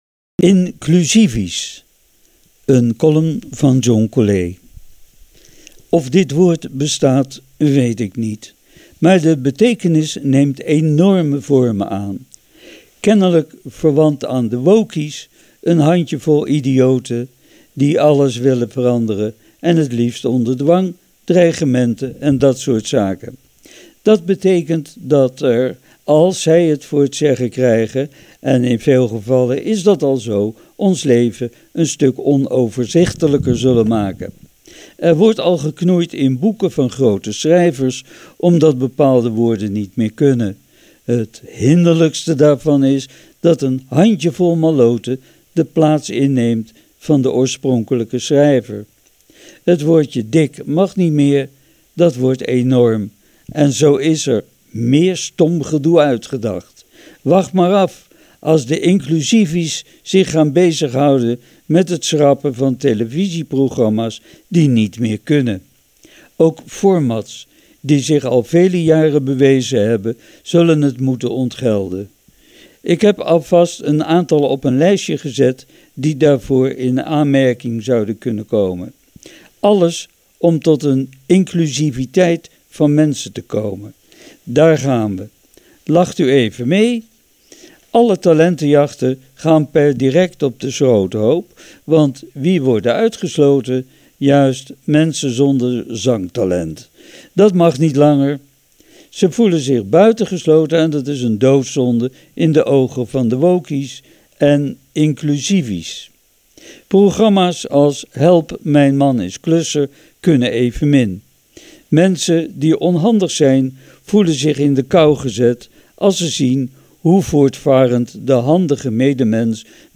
met� dit keerals titel "Inclusivies".Kletskoek is een "Feel Good" programma, dat iedere vrijdag live vanuit de studio vanRadio Capelle wordt uitgezonden tussen 10 en 12 uur.��